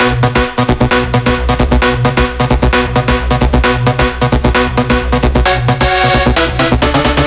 VFH3 132BPM Elemental Melody 1.wav